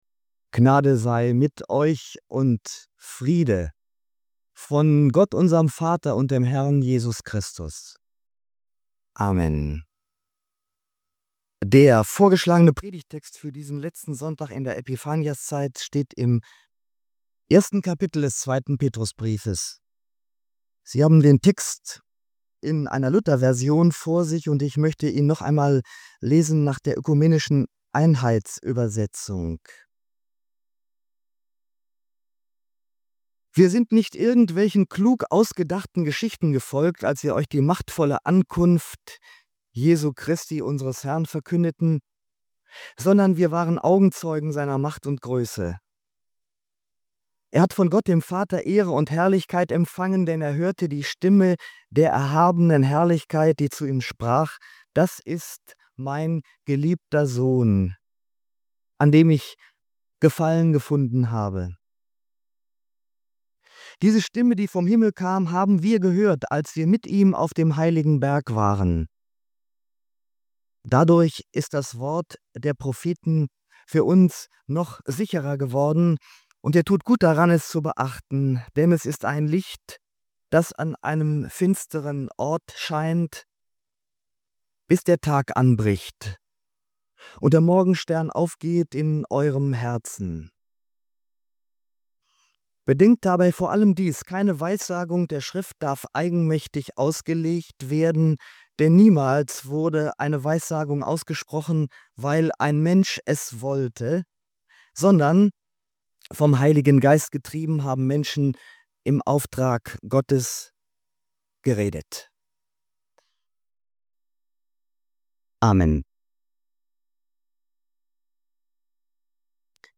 In dieser Predigt zum letzten Sonntag der Epiphaniaszeit steht die Frage im Mittelpunkt, wie wir heute der Bibel begegnen.